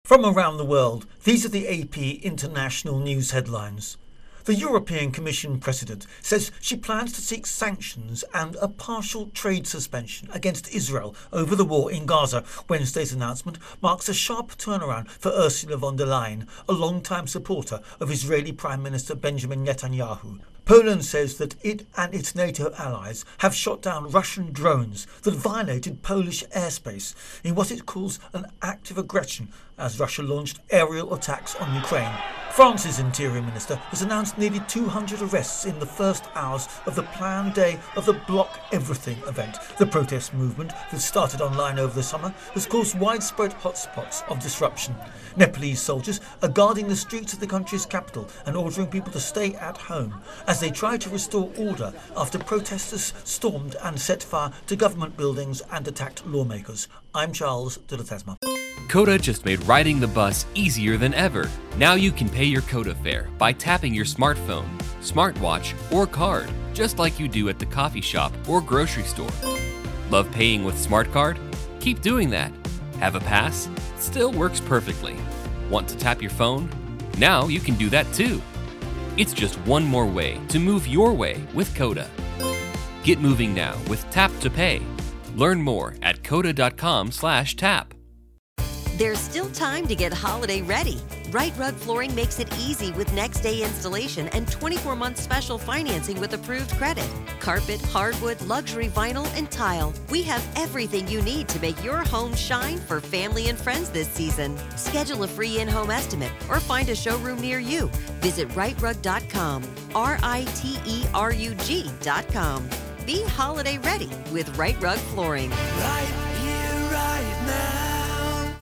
The latest international news